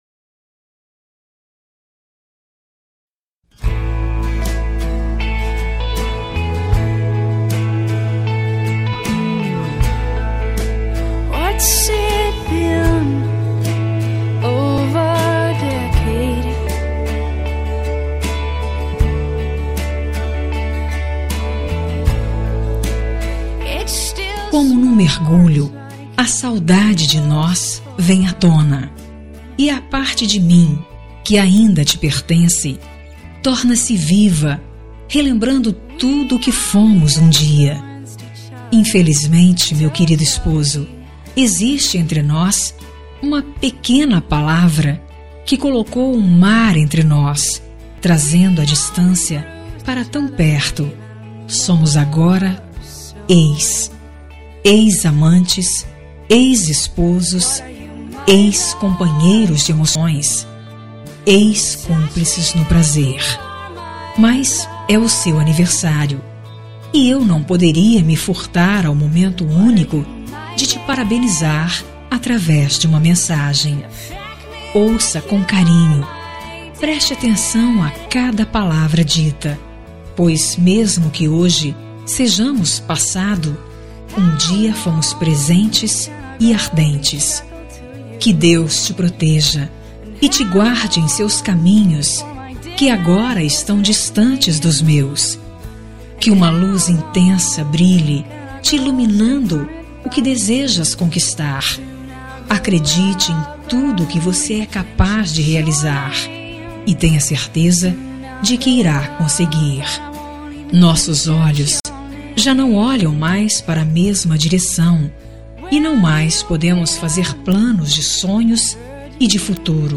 Telemensagem de Aniversário de Ex. – Voz Feminina – Cód: 1367